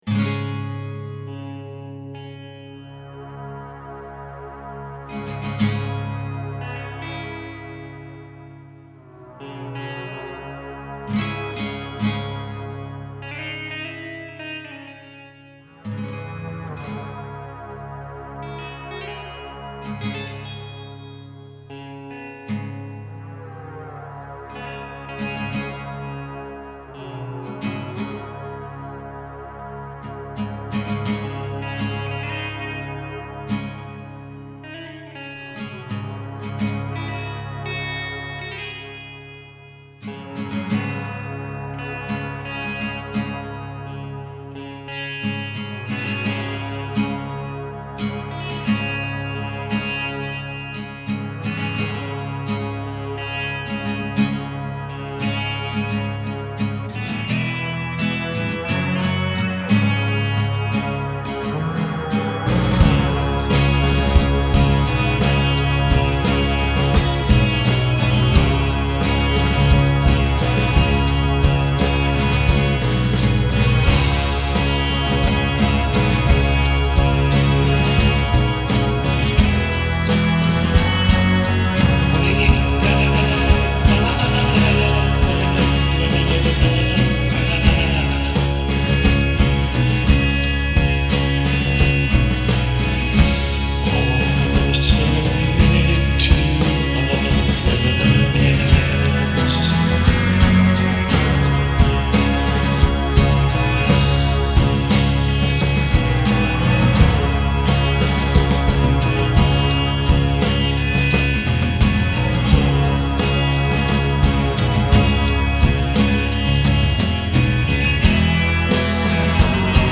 Dark rawk for doobious times.
bass guitar, rhythm guitar, lead guitar
keys, voices
drums